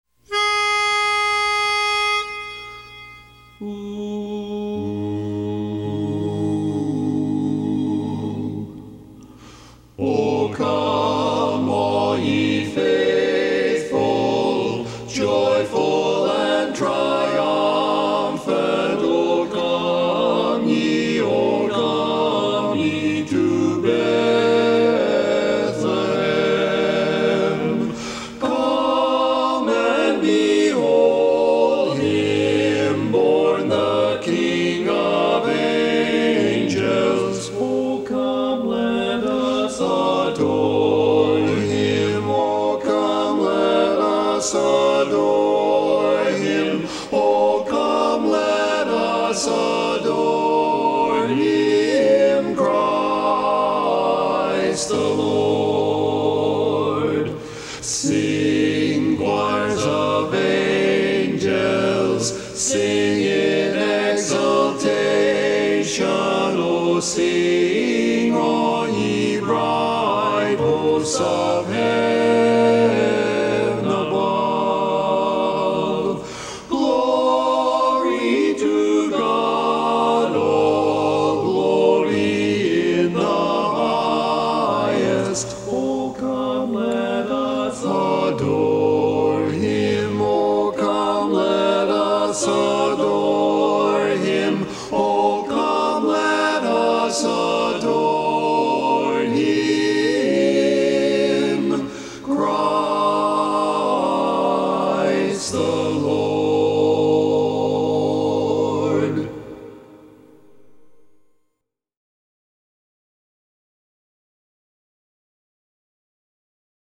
Christmas Songs
Barbershop
Bari